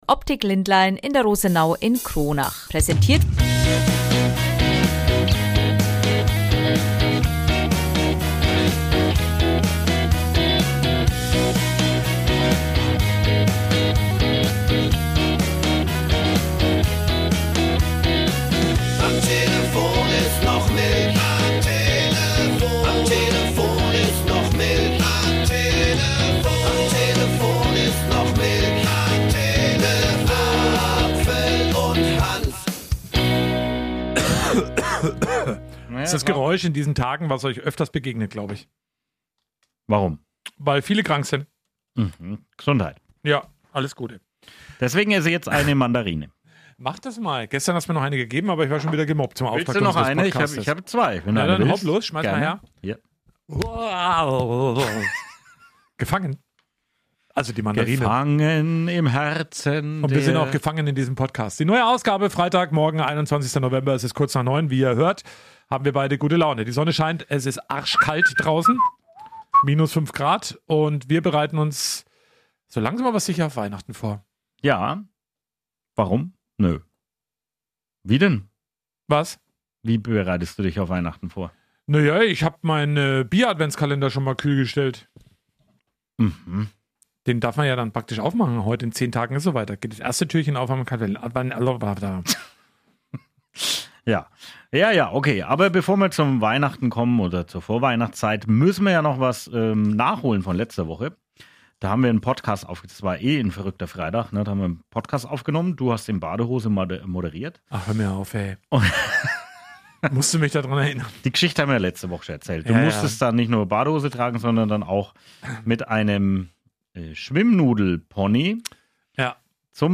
Dazu gibt es viele Berichte und Interviews
mit seinem Küpser Dialekt